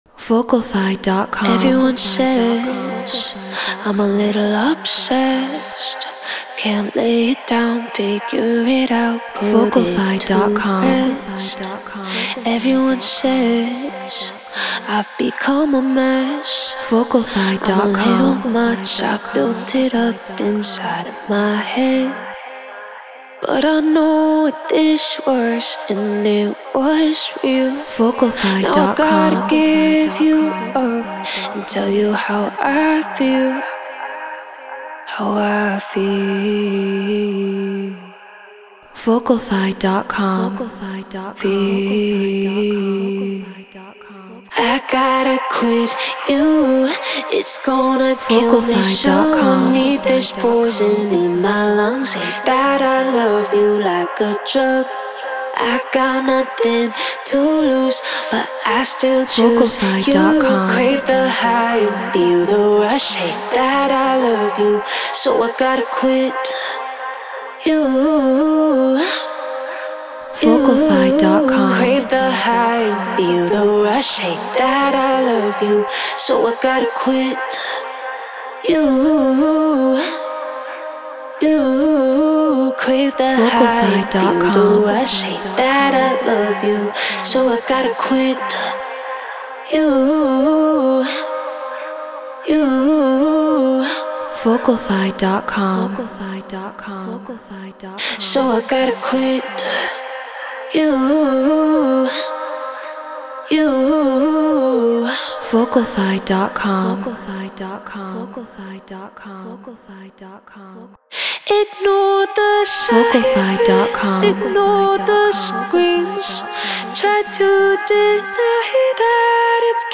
Drum & Bass 174 BPM Fmin
Shure SM7B
Treated Room